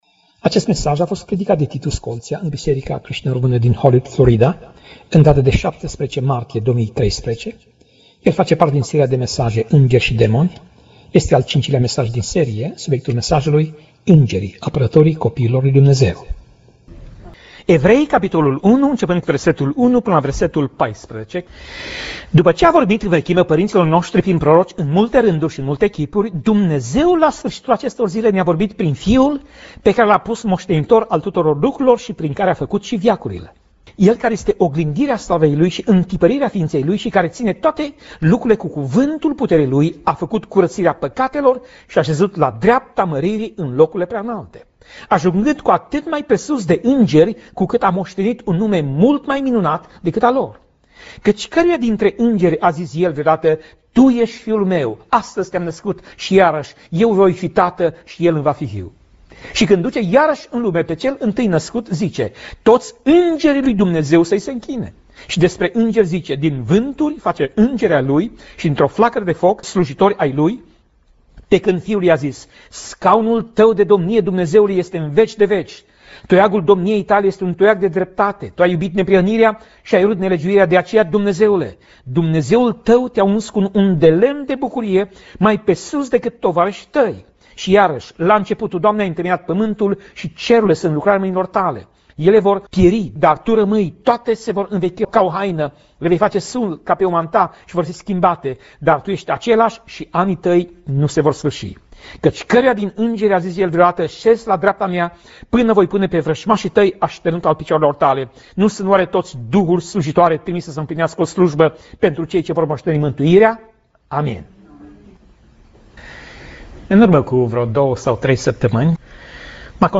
Tip Mesaj: Predica